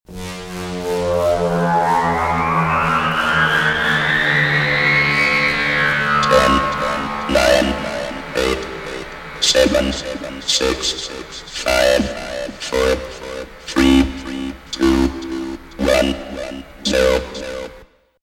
S – COUNTDOWN 10 TO 1 – COMPUTER
S-COUNTDOWN-10-TO-1-COMPUTER.mp3